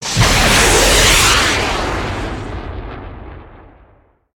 Descarga de Sonidos mp3 Gratis: misil 1.
missle-launch.mp3